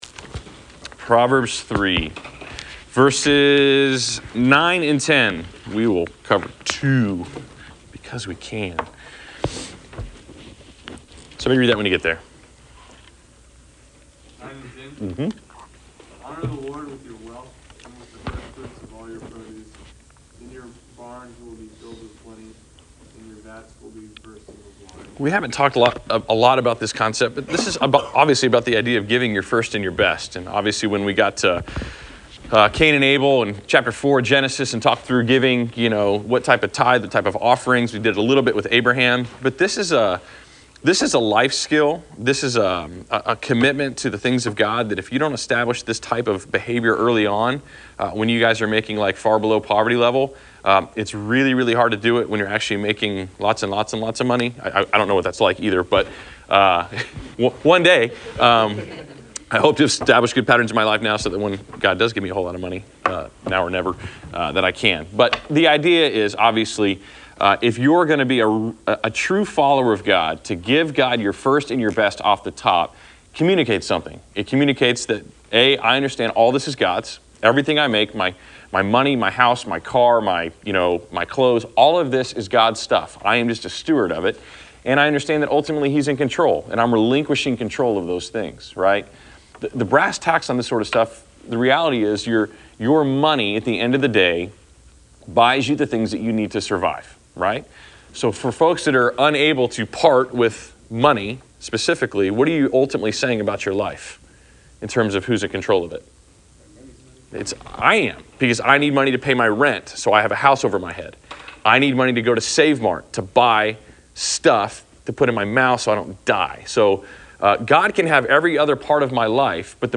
Class Session Audio November 29